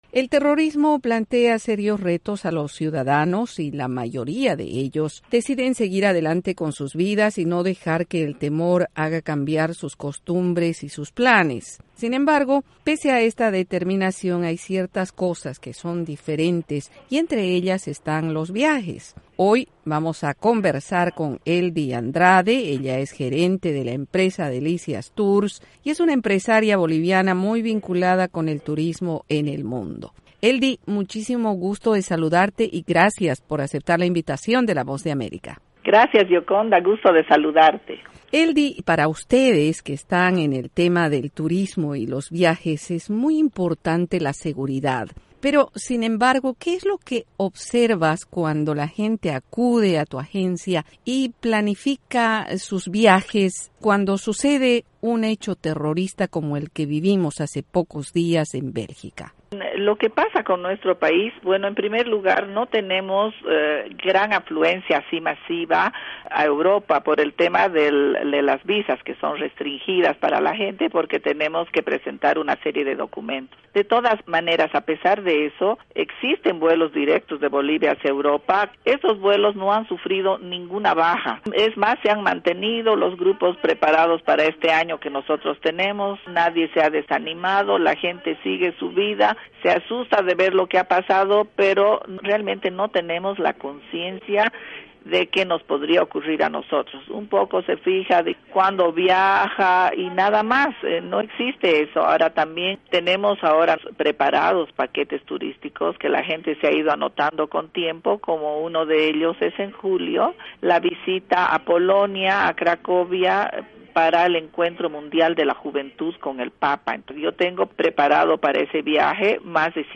Entrevista con la experta en turismo